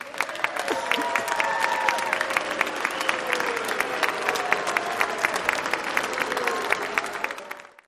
Crowd Boo, Yeah End Speech